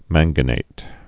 (mănggə-nāt)